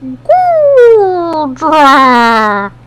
Cries
GOODRA.mp3